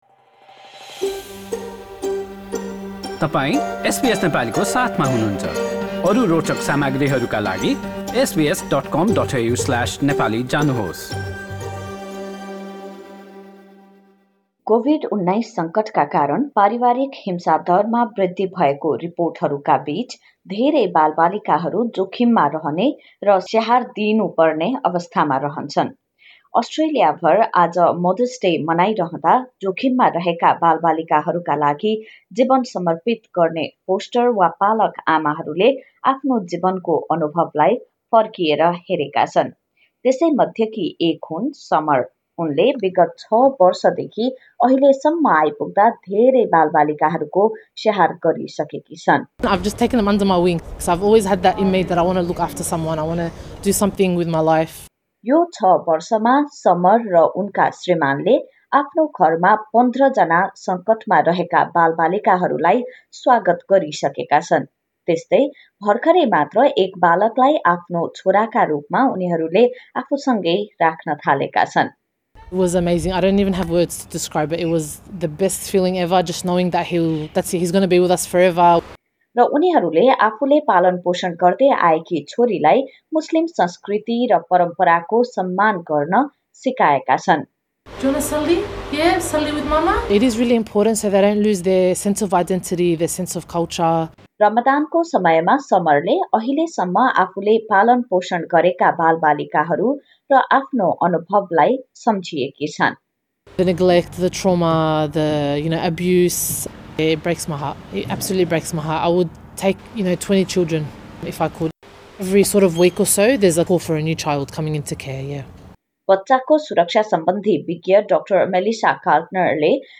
अस्ट्रेलिया भर मदर्स डे अर्थात आमाहरू प्रति समर्पित दिवसको रुपमा मनाइदा आफैंले जन्म नदिए पनि समस्यामा रहेका बालबालिकाहरूका लागी माया र स्याहार प्रदान गर्ने त्यस्ता आमाहरूका बारेमा यो रिपोर्ट।